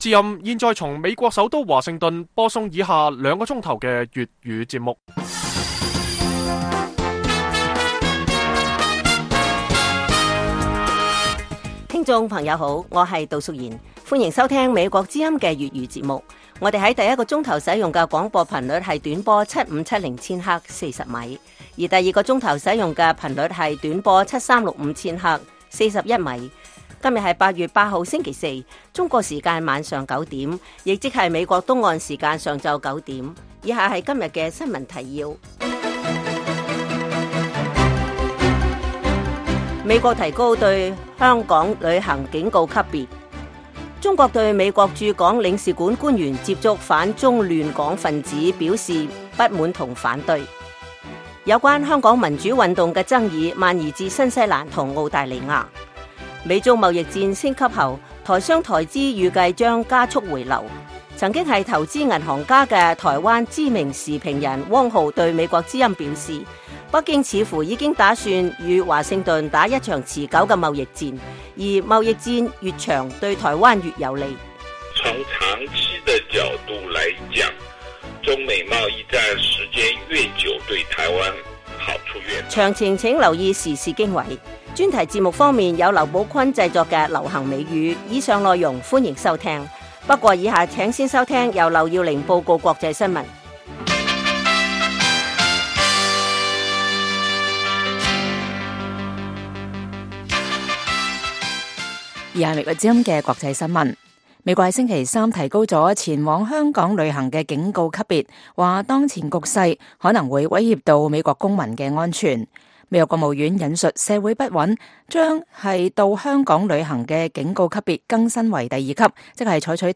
粵語新聞 晚上9-10點
北京時間每晚9－10點 (1300-1400 UTC)粵語廣播節目。內容包括國際新聞、時事經緯和英語教學。